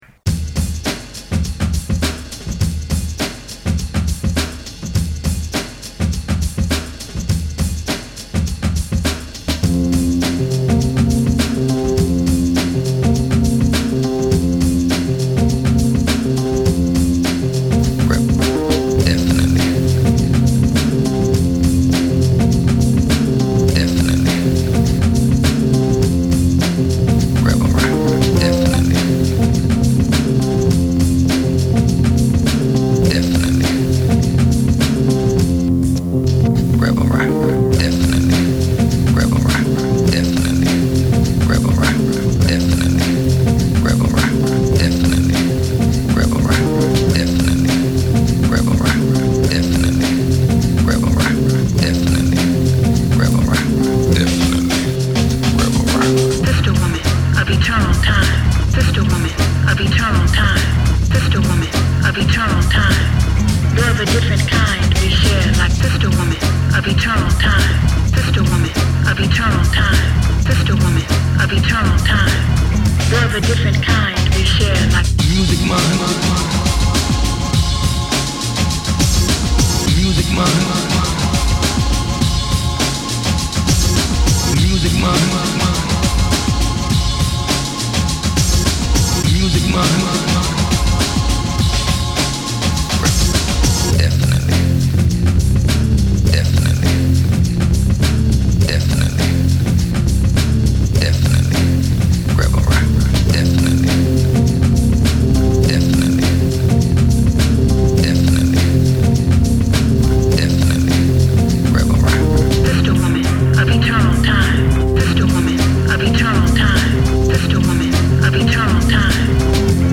All the music was built with a sampler and keyboard from the stuff people sent in - with some of the ads mxed in there too.
There are Windows sounds all over it.